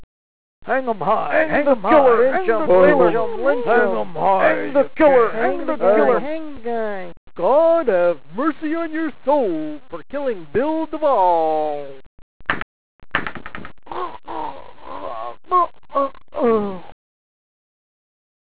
Hanging (Old West) NECRHQ635